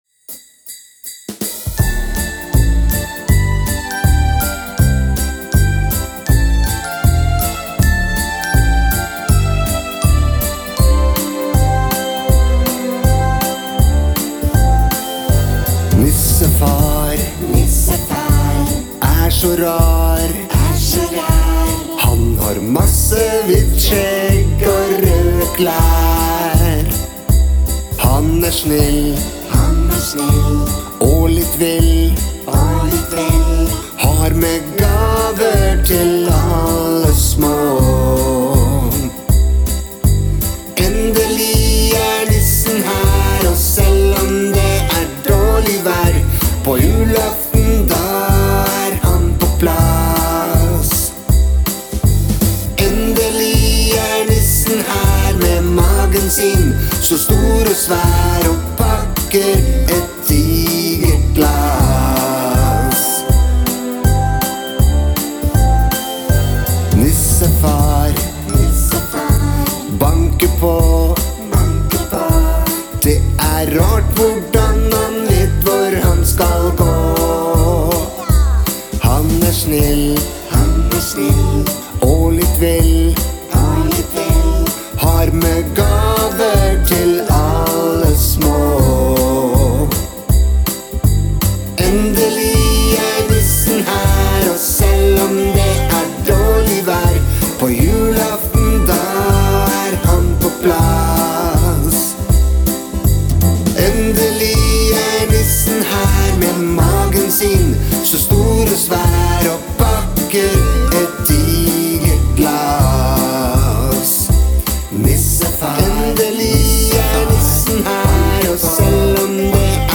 Endelig er det tid for julesanger
Denne ble koselig
Hvis jeg hadde hatt bedre tid, ville jeg laget korstemmene renere og mer synkronisert.